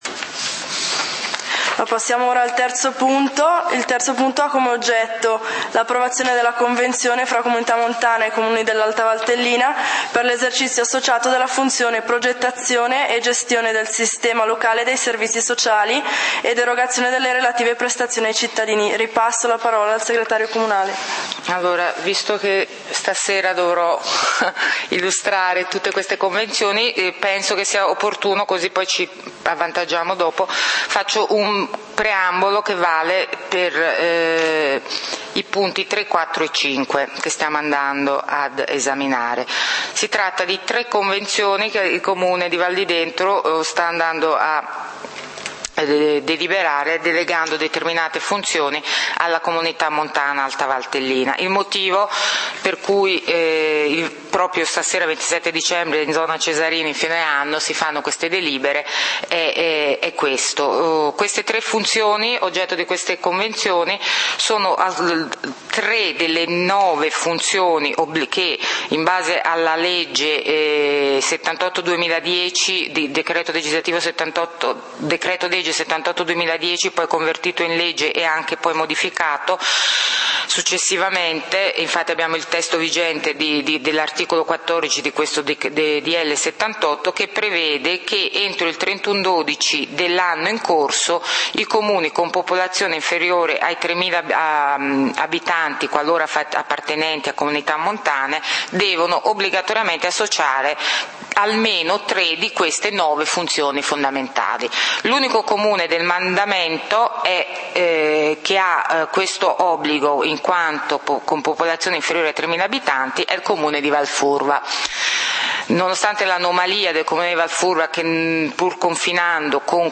Consiglio comunale del 27 Dicembre 2012